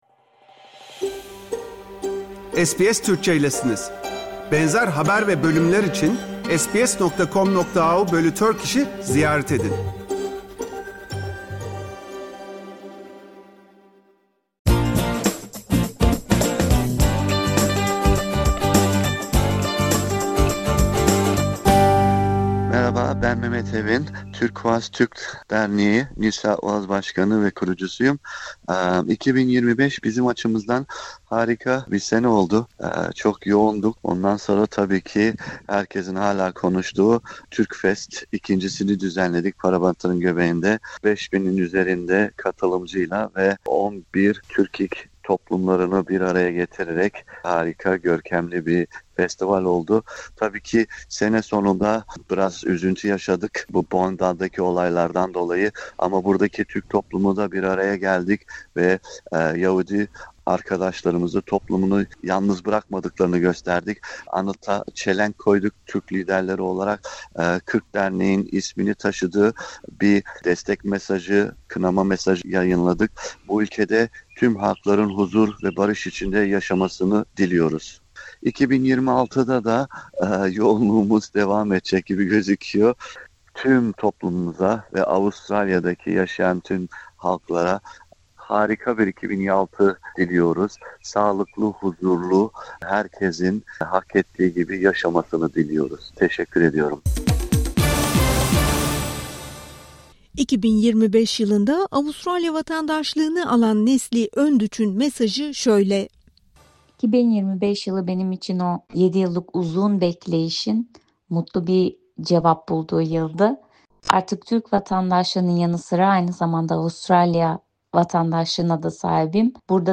Avustralyalı Türk toplum bireylerimiz biten 2025 yılını değerlendirdi, 2026 yılından beklentilerini ve dileklerini mikrofonumuza anlattı...